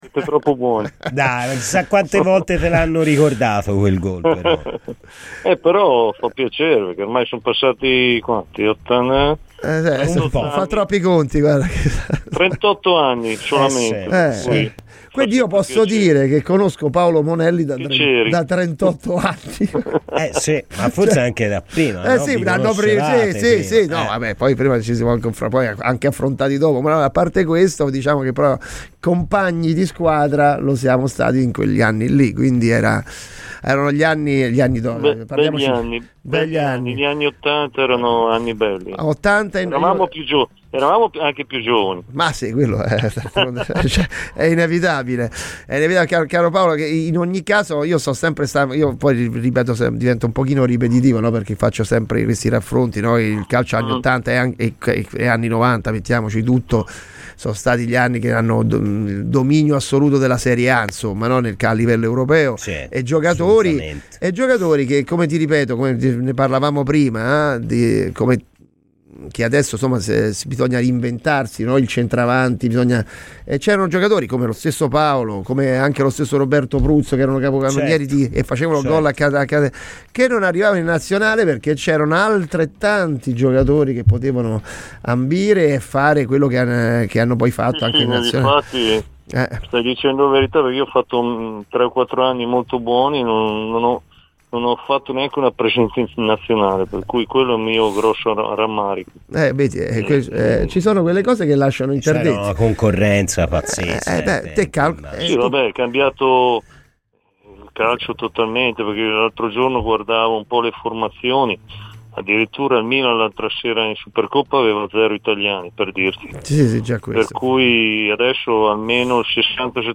è intervenuto durante la trasmissione di "Colpi d'Ala" su RadioFirenzeViola